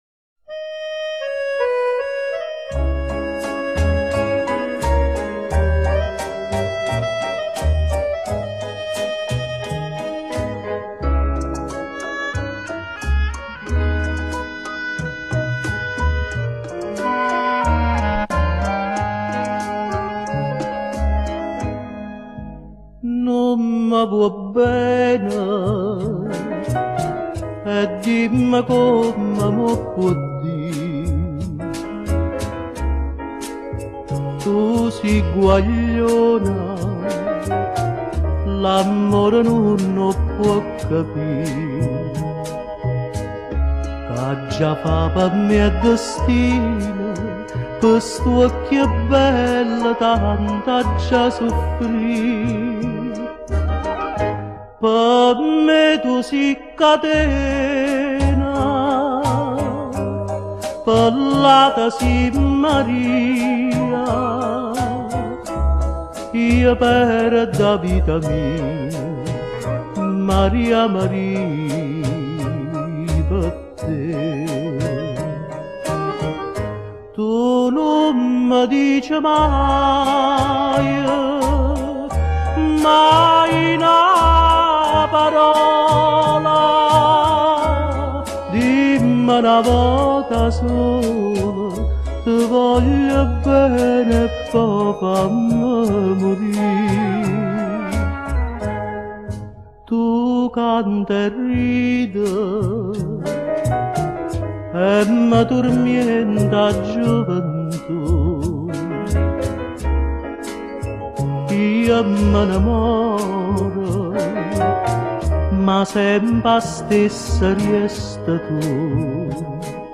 Классика итальянской песни